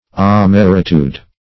Search Result for " amaritude" : The Collaborative International Dictionary of English v.0.48: Amaritude \A*mar"i*tude\, n. [L. amaritudo, fr. amarus bitter: cf. OF. amaritude.]